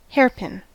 Ääntäminen
IPA : /ˈheɪrpɪn/ IPA : /ˈheɚ.pɪn/